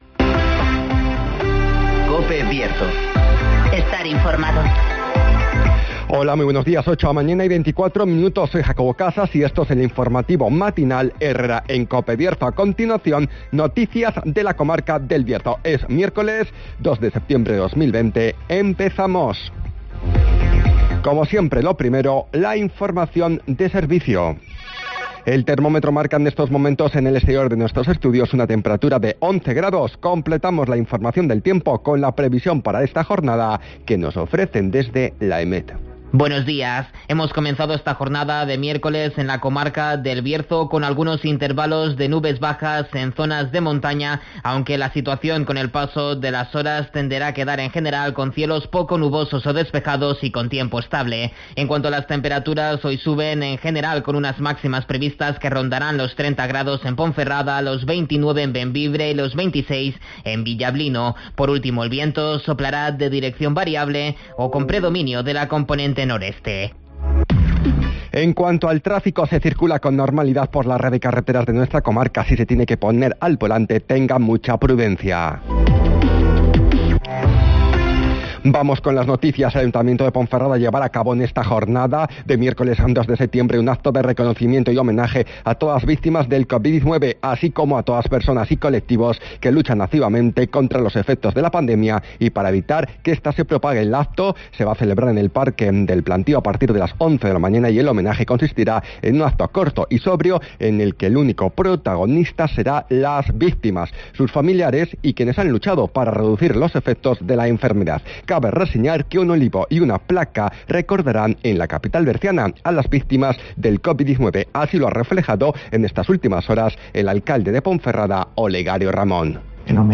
INFORMATIVOS
Conocemos las noticias de las últimas horas de nuestra comarca, con las voces de los protagonistas
-Declaraciones de Olegario Ramón, alcalde de Ponferrada